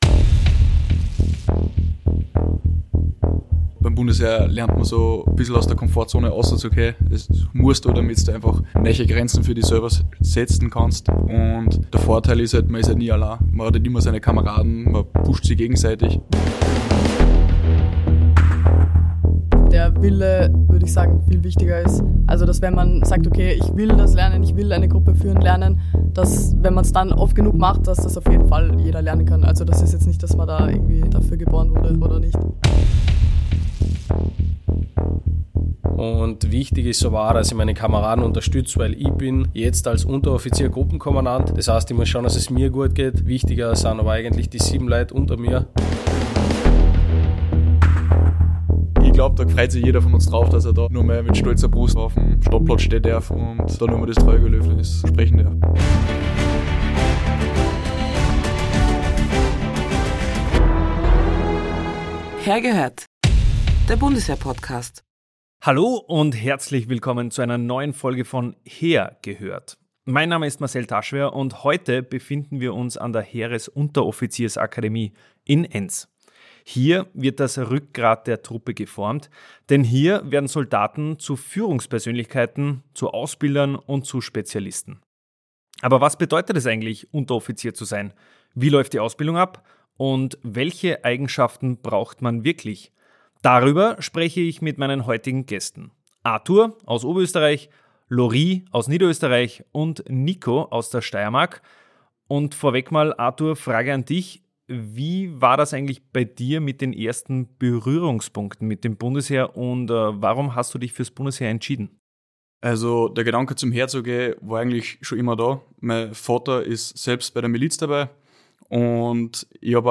Im Gespräch erzählen sie von spannenden Momenten in ihrer Ausbildung und warum sie sich dafür entschieden haben.